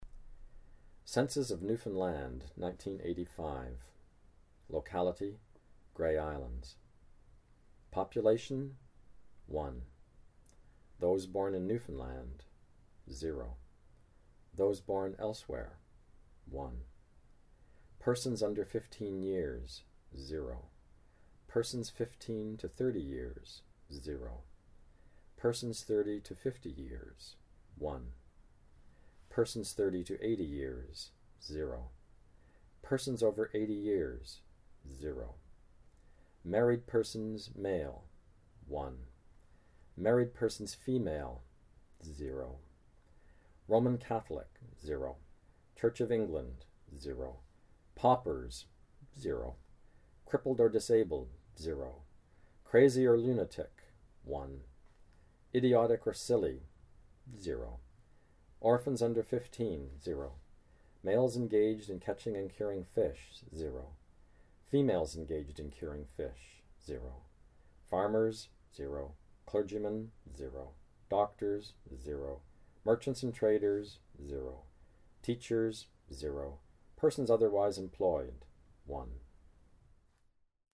John Steffler reads Census of Newfoundland 1985 from The Grey Islands